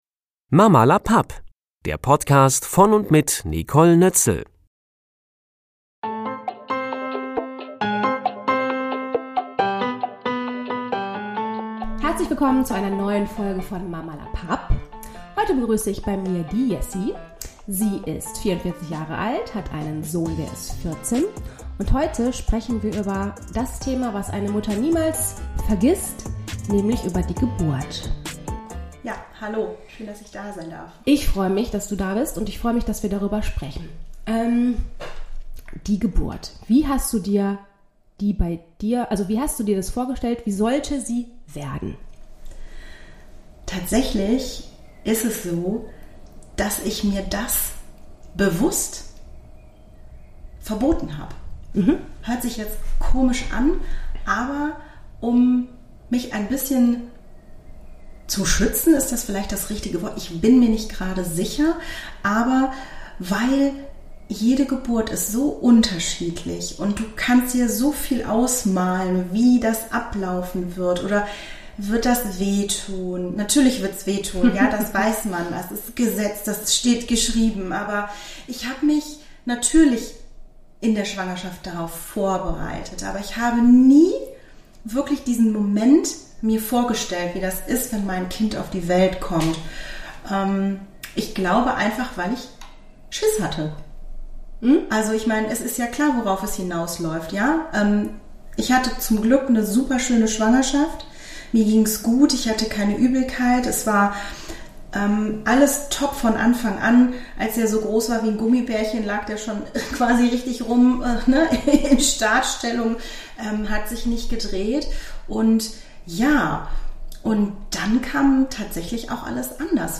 Warum sie sich das auch viele Jahre später nicht verzeihen kann und was ihr geholfen hat, mit dem Thema Frieden für sich zu schließen, das vertraut sie mir in unserem Gespräch an.